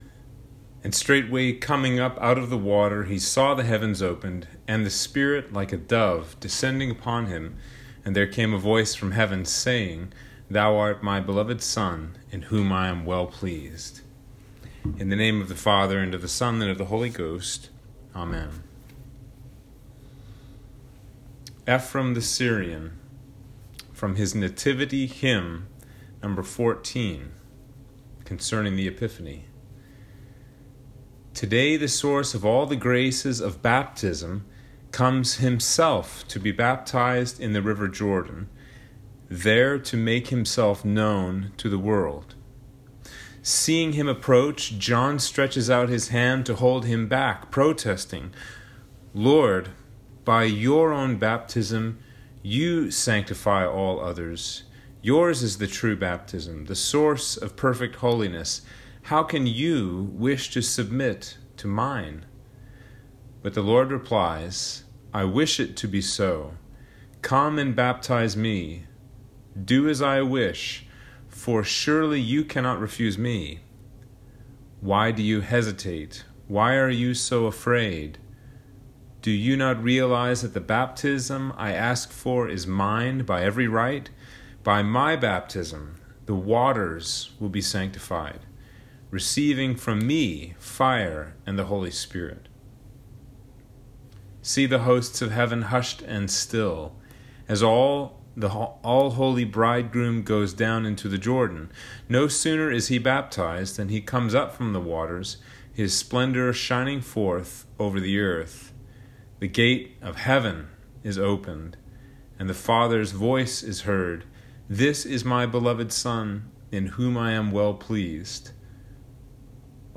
Sermon for Epiphany 2 - 2022